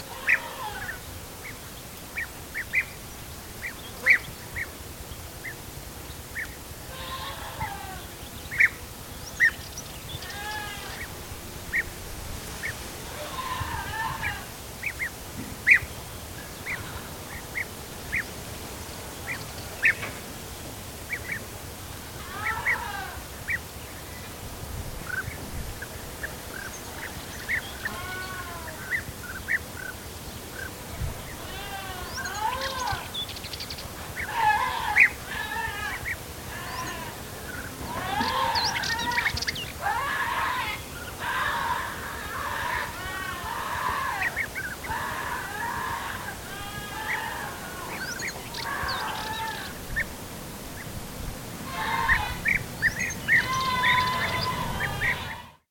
BeeEatersAndBirth
Category 🌿 Nature
birds cry cyprus field-recording goat groan nature pain sound effect free sound royalty free Nature